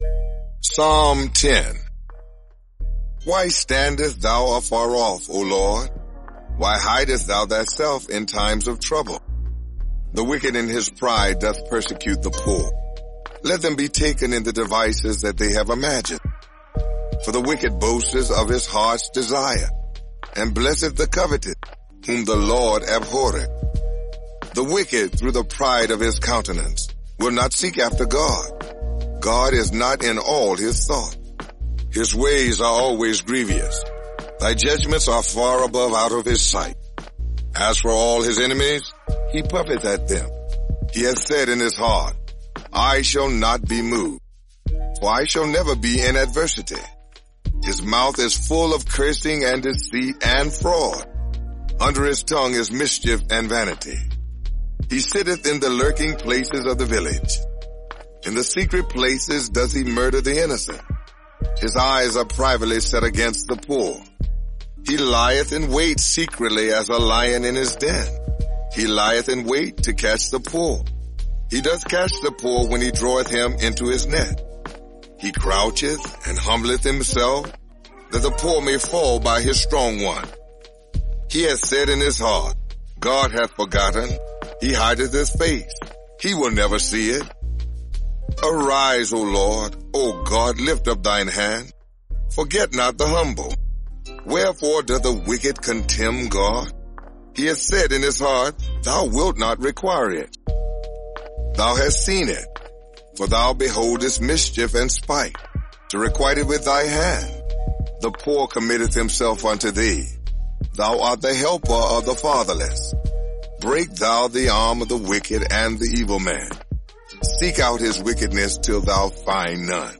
Experience the Psalms in a fresh new way! This narrated devotional provides encouraging insight over original music that injects hope, faith, wisdom, inspiration, and so much more through the Psalms!